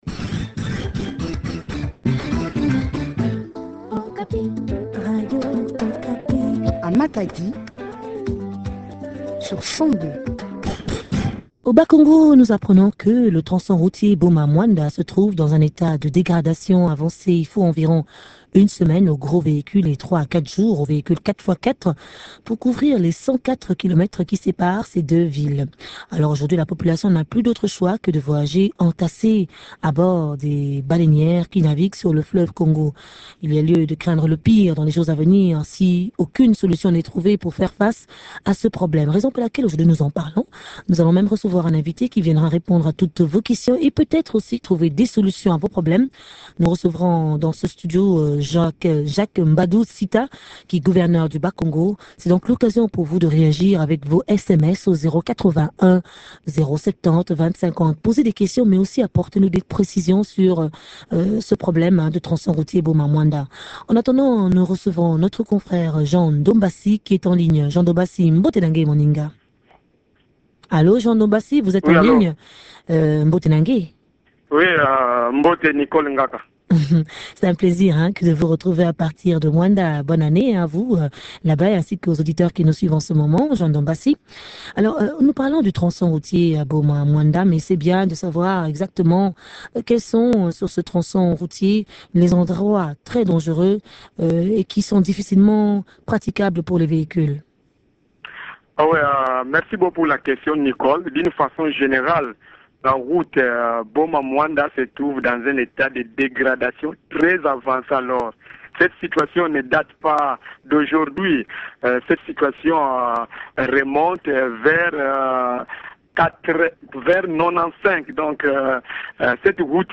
Jacques Mbadu Situ, gouverneur du Bas-Congo.